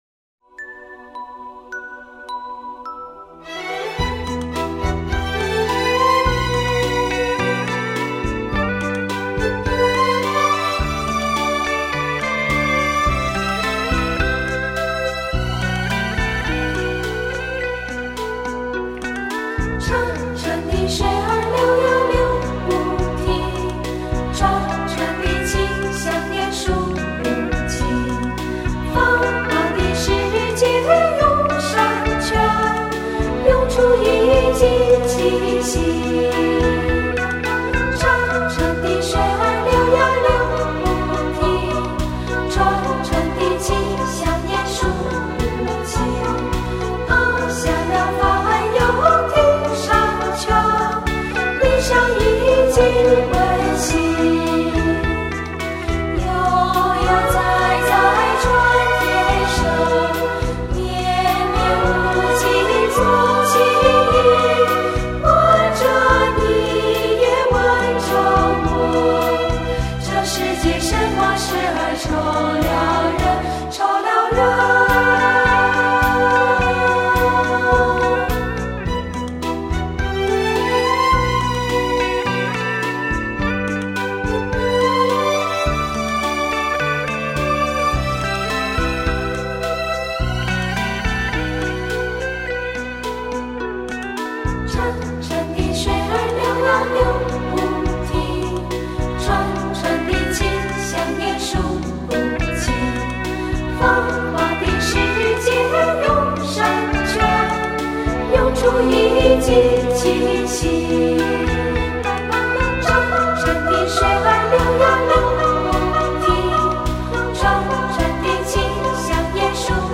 透过天使的歌声，嗅到了乡间的味道。
优美的和声